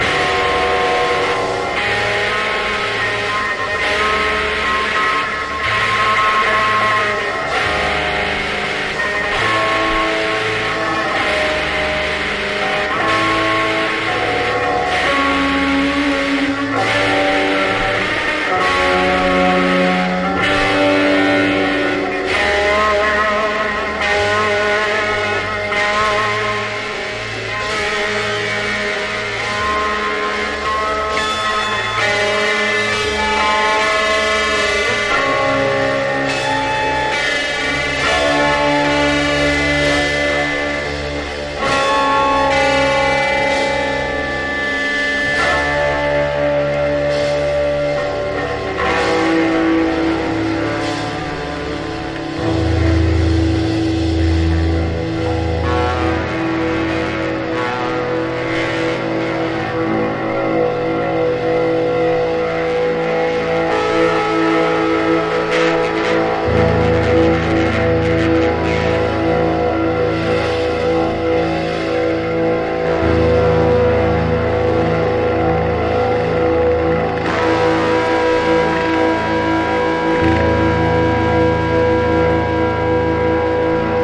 英国カルト・ポストパンク/エクスペリメンタル・バンド唯一のフル・アルバム！